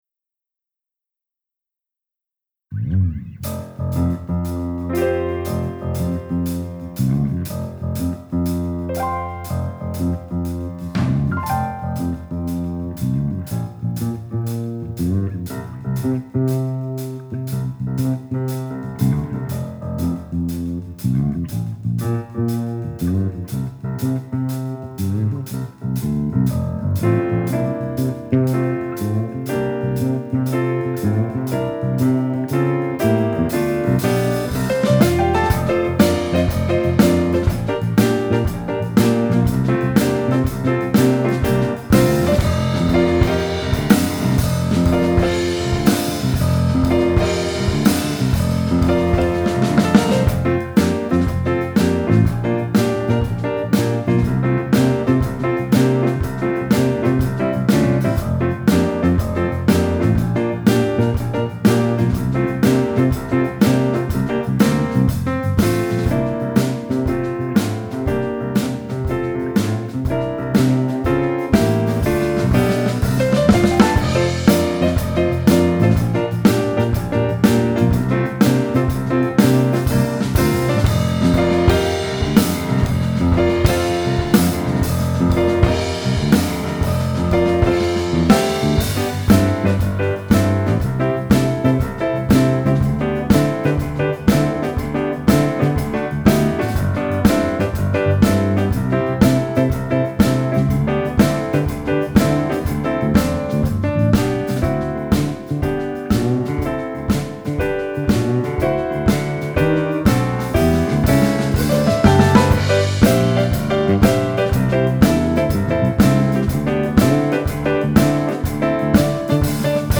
Joyful_Joyful_Backing_Track.mp3